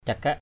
/ca-kaʔ/